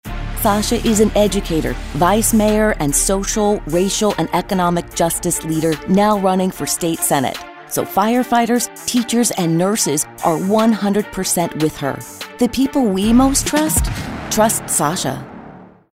announcer, confident, informative, newscaster, political